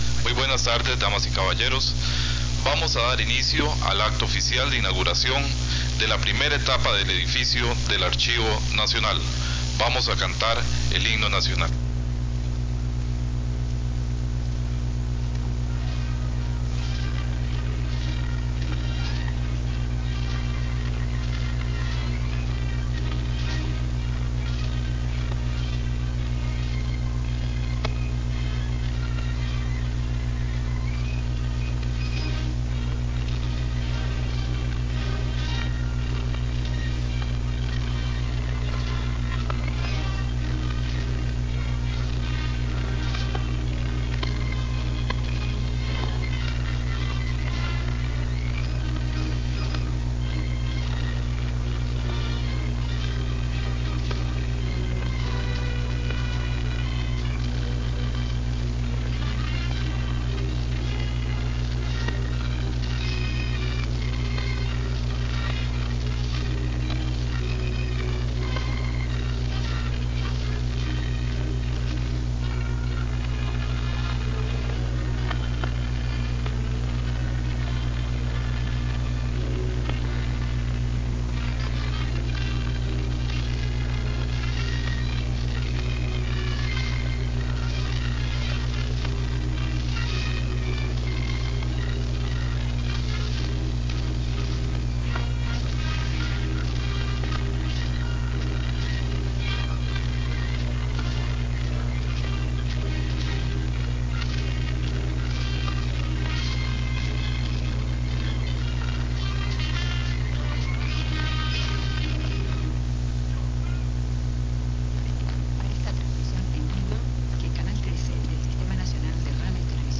Acto de inauguración de Seminario sobre Archivos - Archivo Nacional de Costa Rica
Notas: Casete de audio y digital